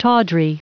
Prononciation du mot tawdry en anglais (fichier audio)
Prononciation du mot : tawdry